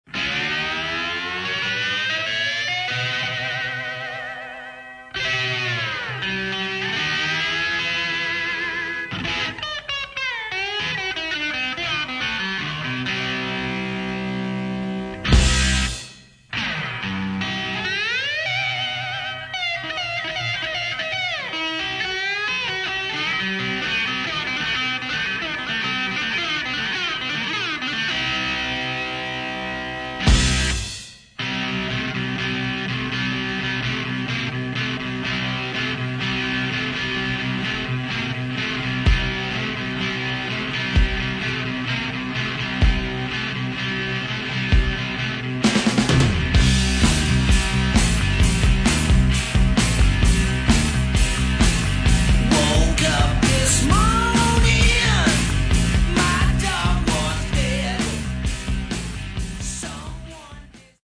Рок
Великолепны все партии – вокал, гитары, барабаны.